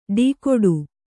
♪ ḍīkoḍu